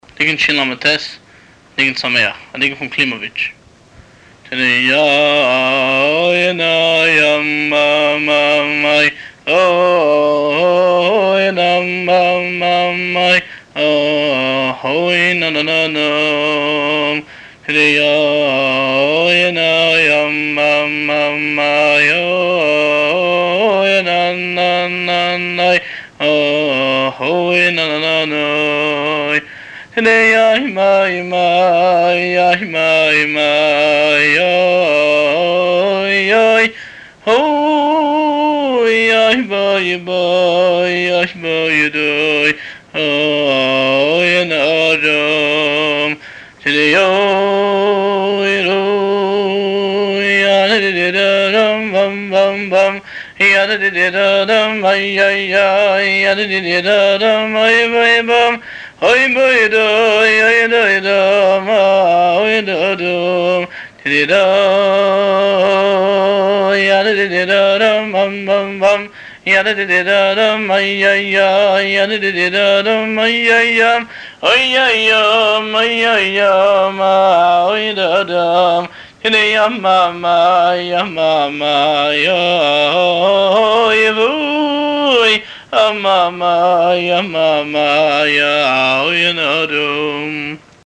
הניגון